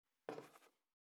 223,机に物を置く,テーブル等に物を置く,食器,
コップ